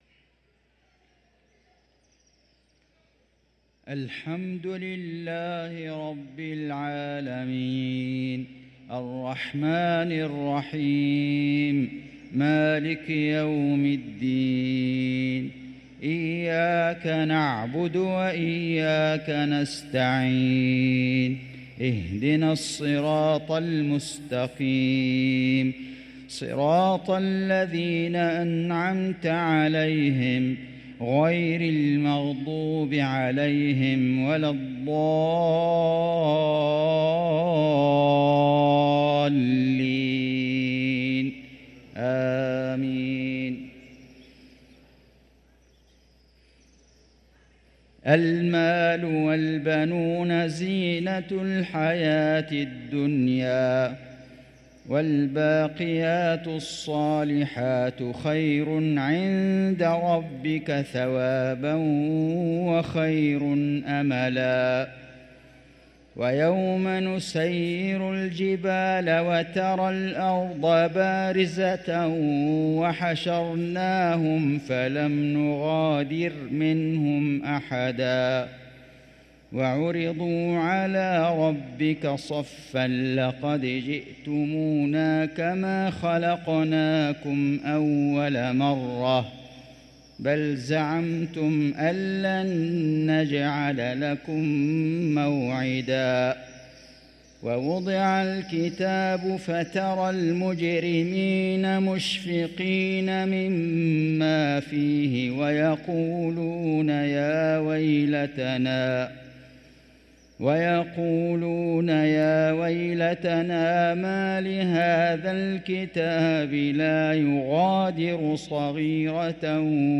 صلاة العشاء للقارئ فيصل غزاوي 19 رجب 1445 هـ
تِلَاوَات الْحَرَمَيْن .